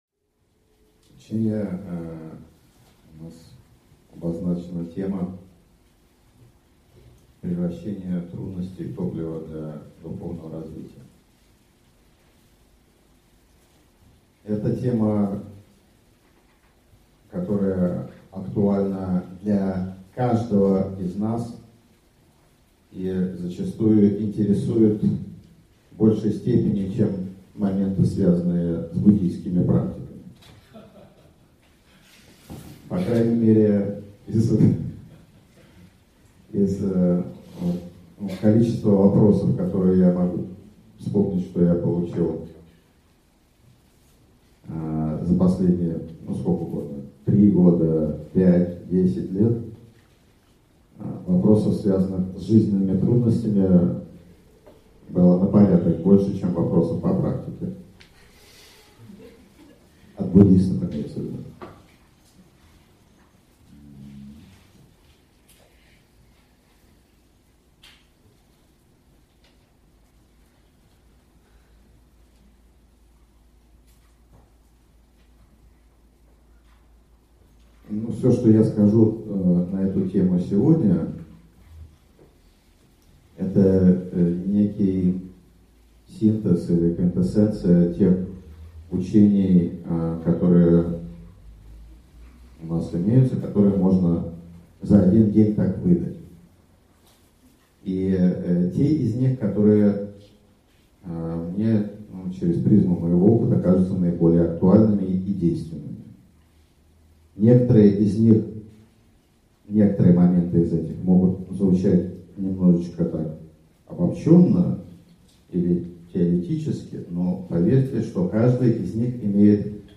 Аудиокнига Превращение трудностей в топливо для духовного развития | Библиотека аудиокниг